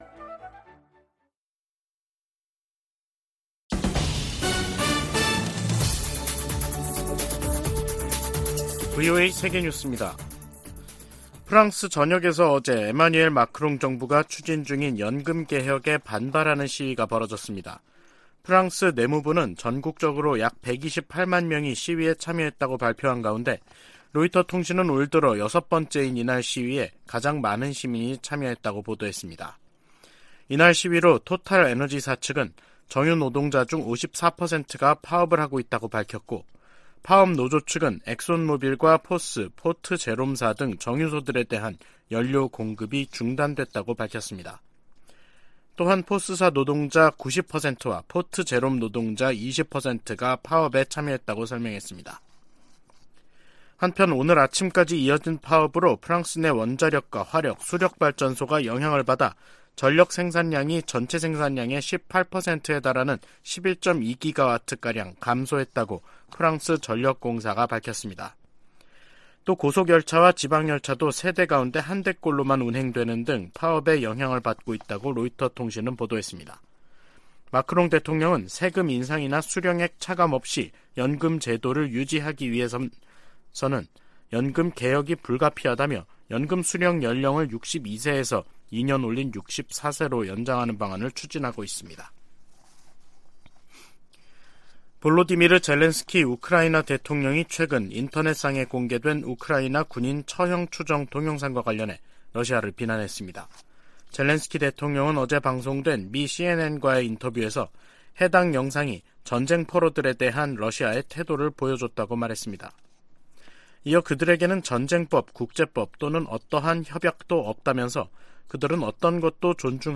VOA 한국어 간판 뉴스 프로그램 '뉴스 투데이', 2023년 3월 8일 3부 방송입니다. 백악관은 윤석열 한국 대통령이 다음 달 26일 미국을 국빈 방문한다고 밝혔습니다. 미국 사이버사령관이 미국 정치에 개입하려 시도하는 상위 4개국으로 북한과 중국, 러시아, 이란을 꼽았습니다. 한국은 전시작전통제권 전환을 위한 일부 역량을 키웠지만 연합방위를 주도할 능력을 입증해야 한다고 전 주한미군사령관이 지적했습니다.